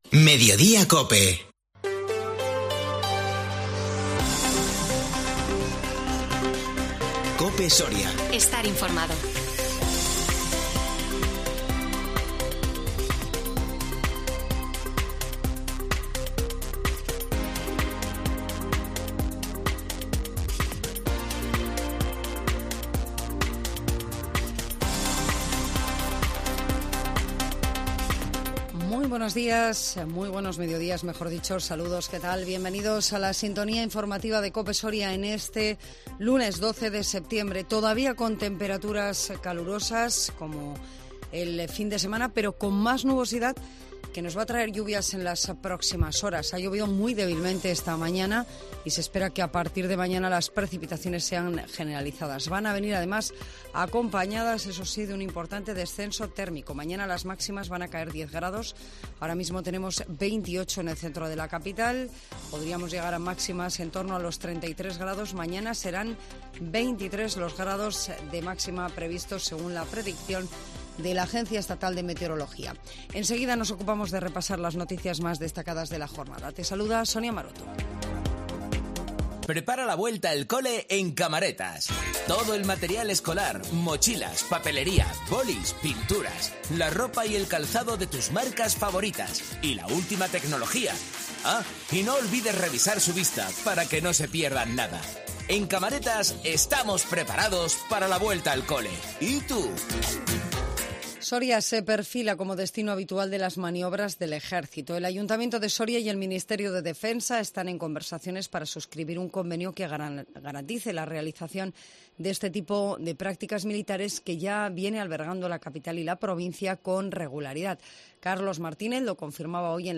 INFORMATIVO MEDIODÍA COPE SORIA 12 SEPTIEMBRE 2022